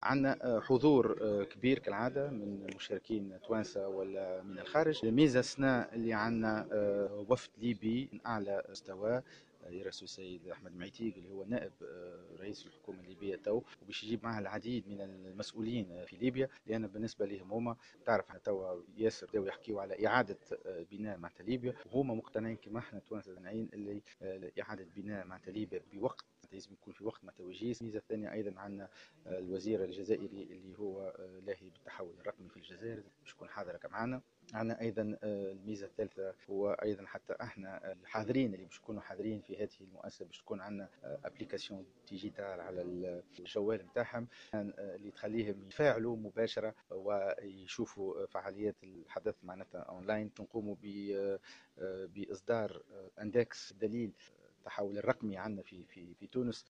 وقال على هامش ندوة صحفية عقدها ان التظاهرة ستشهد مشاركة عدد كبير من الشخصيات والمؤسسات إلى جانب حضور مسؤولين اجانب رفيعي المستوى بينهم الوفد الليبي والوفد الجزائري.(صورة أرشيفية)